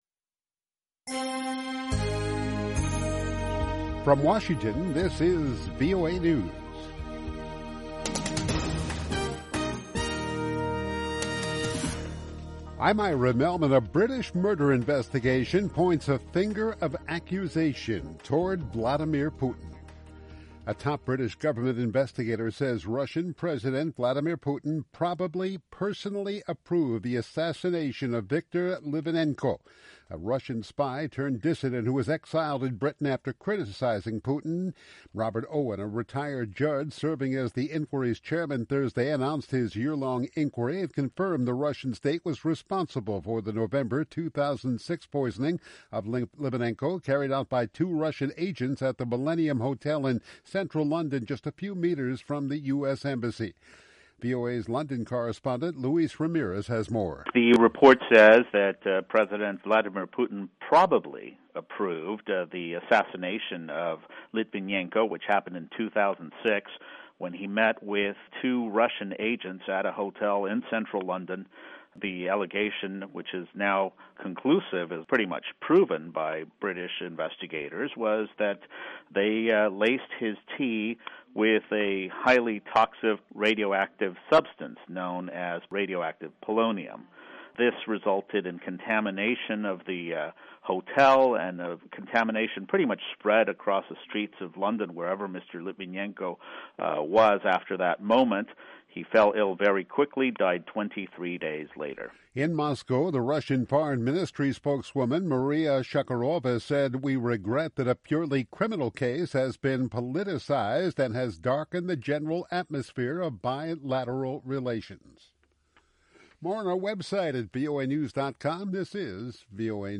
VOA English Newscast: 1900 UTC January 21, 2016 From Washington, this is VOA News.